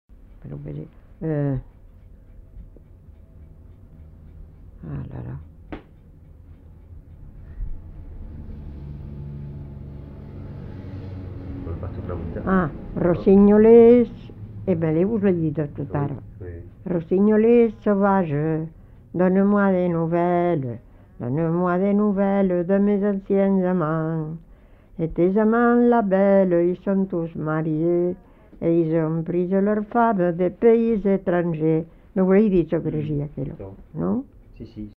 Aire culturelle : Haut-Agenais
Lieu : Fumel
Genre : chant
Effectif : 1
Type de voix : voix de femme
Production du son : chanté